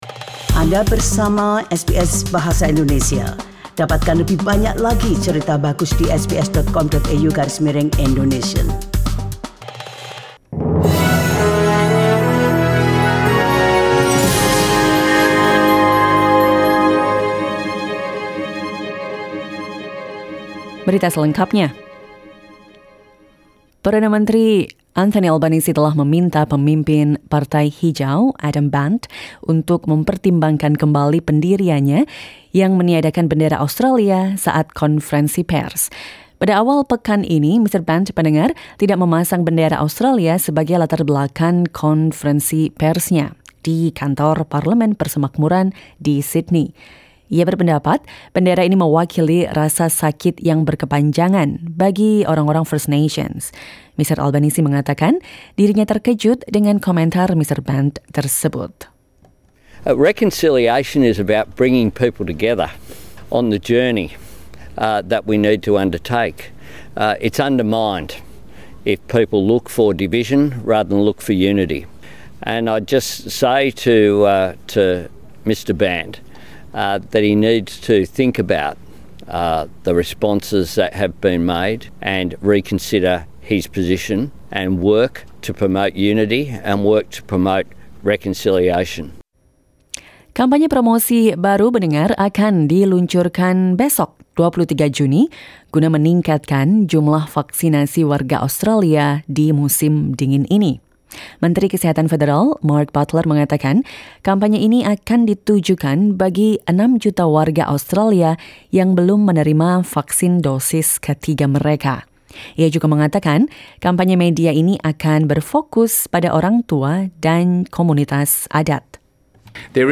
SBS Radio news in Indonesian - 22 June 2022